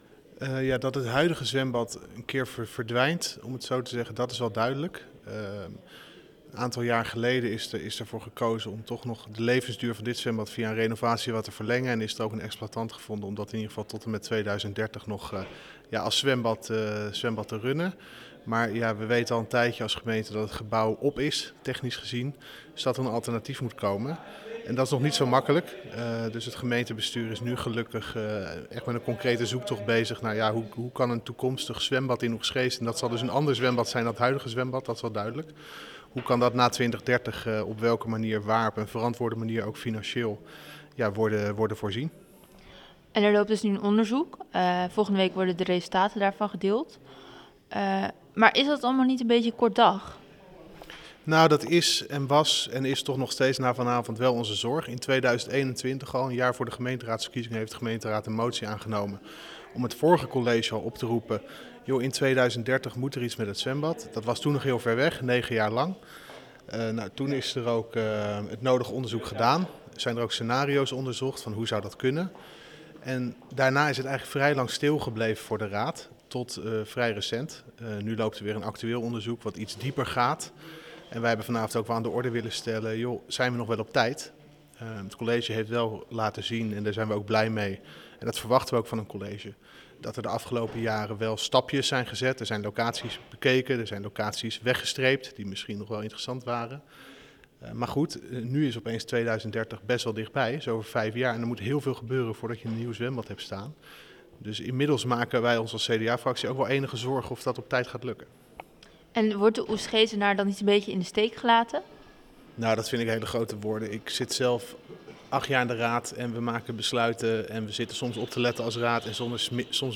CDA-raadslid Tobias van der Hoeven in gesprek